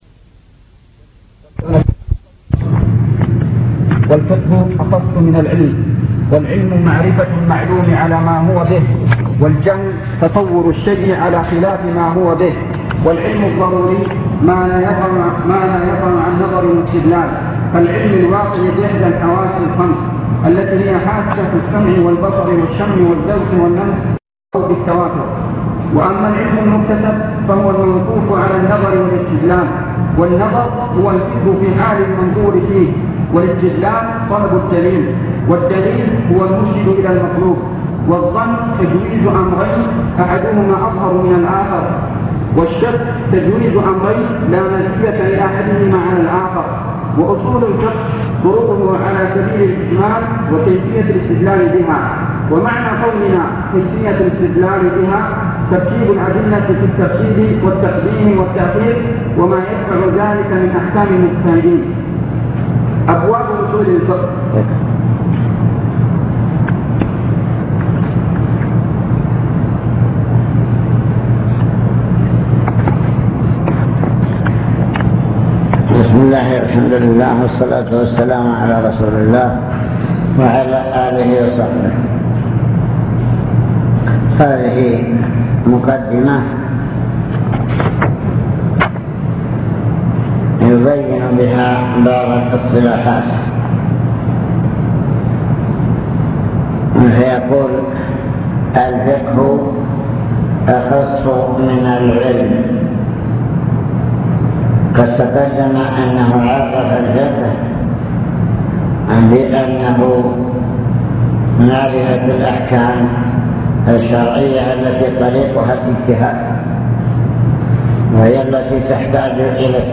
المكتبة الصوتية  تسجيلات - محاضرات ودروس  محاضرة في وادي ثرجوم